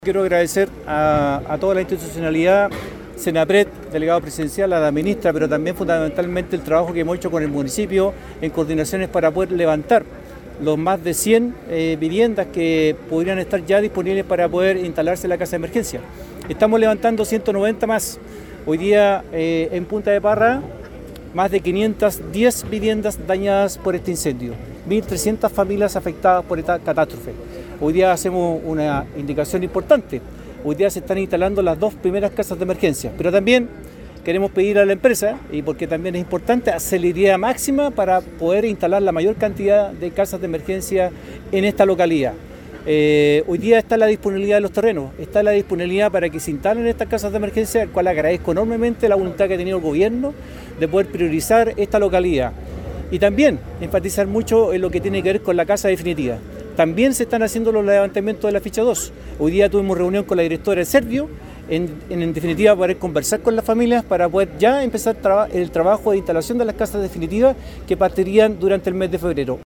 En tanto, el alcalde de Tomé, Ítalo Cáceres, agradeció “a toda la institucionalidad” para el levantamiento de más de 190 viviendas en esa comuna, y valoró las gestiones para que la instalación de viviendas definitivas comience ya en febrero.